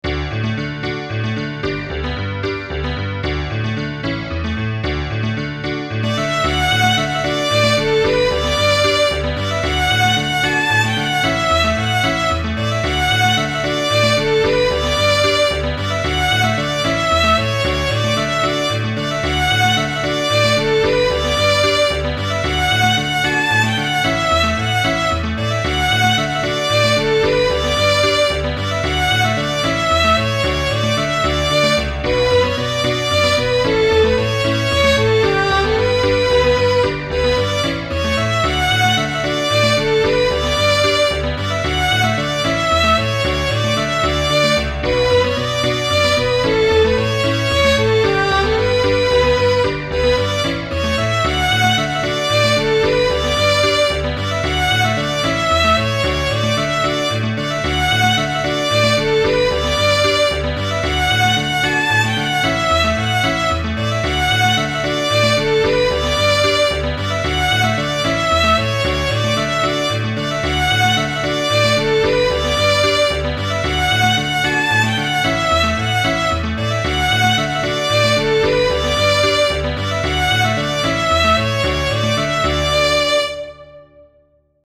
I can't play this to speed and am still working on the bowing, but this is a fun tune, the title being self-explanatory.
IMHO the tempo is great, well done, awesome job! 🙂
Very up beat song has a very good sound.    thumbs-up